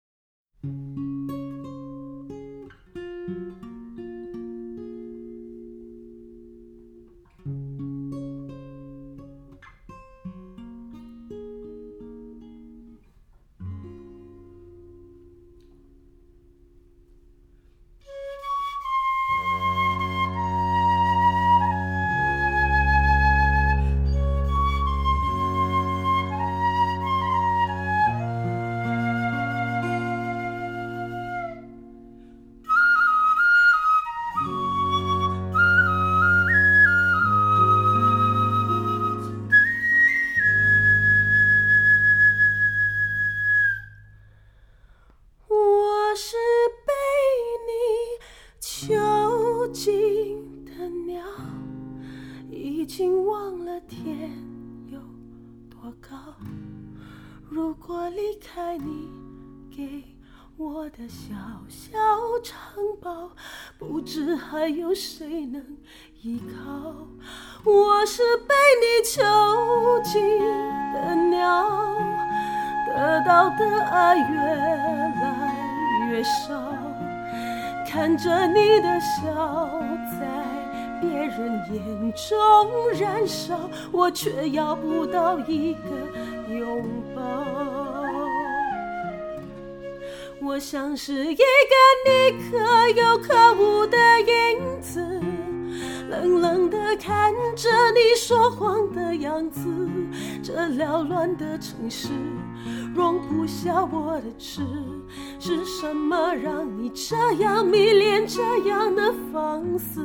純熟的演唱技巧、收放自如的情感投入加上發燒級的單點錄音，絕對讓您驚豔的女聲！
所有樂手一次完成，完全未經過任何壓縮或混音，直入雙軌母帶，盡顯原汁原味！